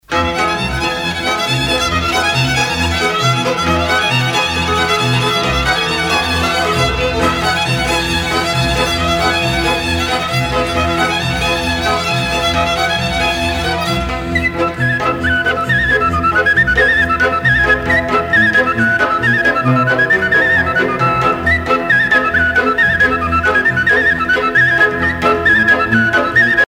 danse : joc de doi (Roumanie)
Pièce musicale éditée